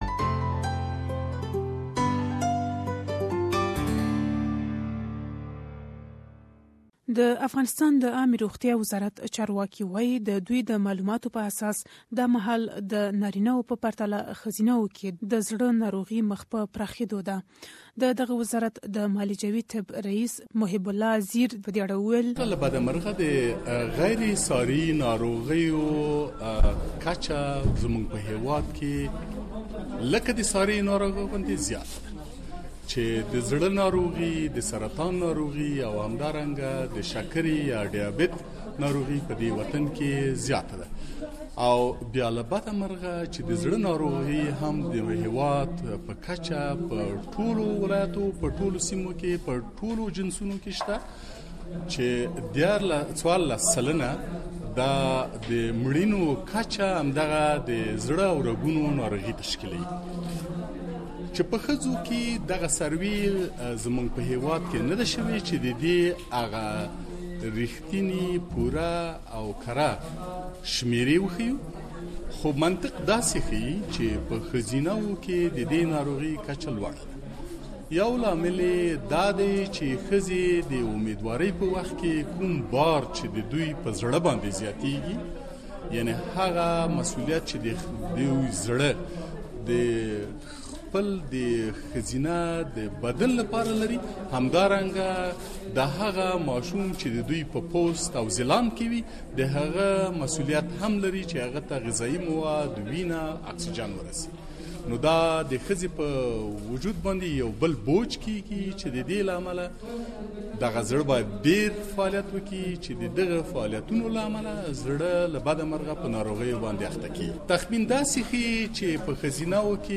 please listen to the full report in Pashto Share